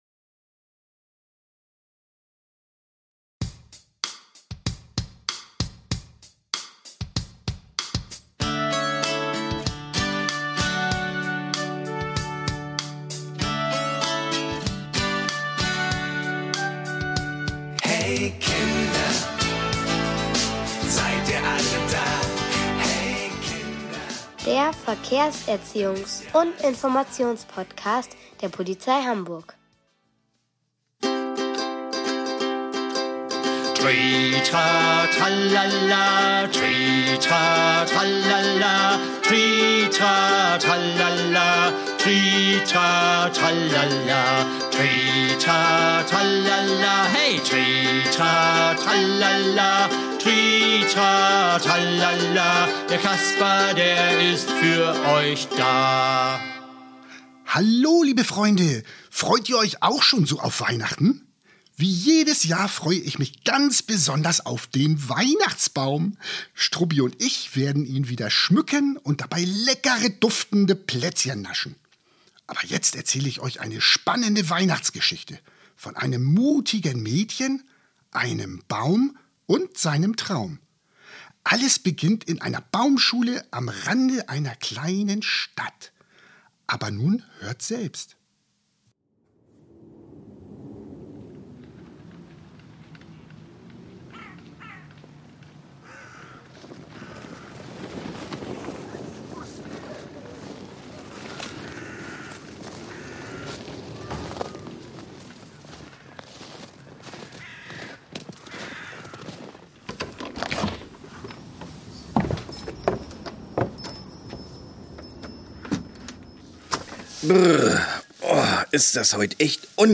Seid dabei und erlebt es selbst mit dem spannenden Hörspiel und tollen Liedern zum Mitsingen!